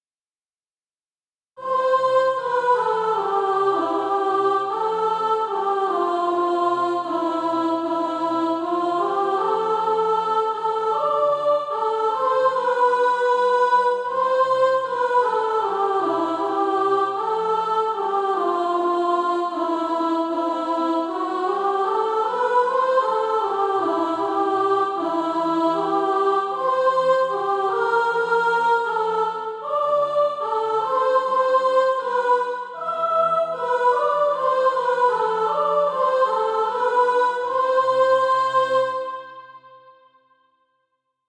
Soprano Track.
(SATB) Author
Practice then with the Chord quietly in the background.